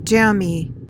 PRONUNCIATION: (JAM-ee) MEANING: adjective: 1.